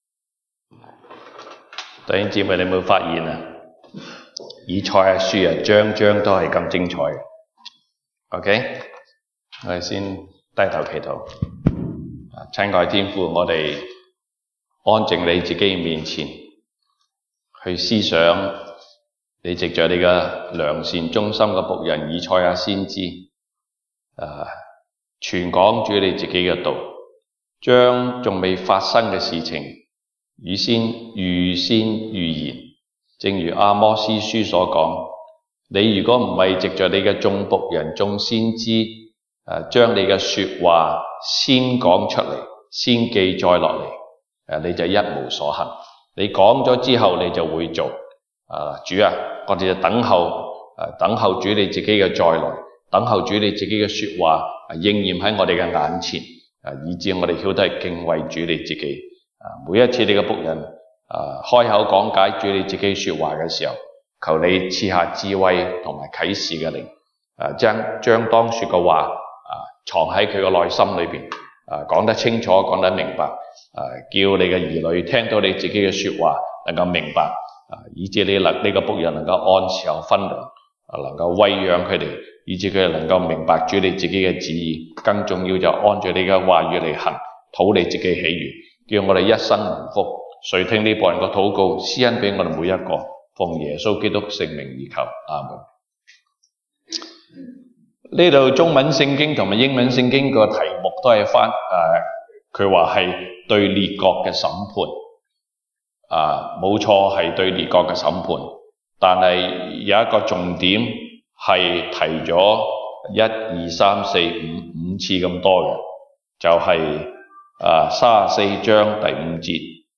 東北堂證道 (粵語) North Side: 神必按照祂所說的行事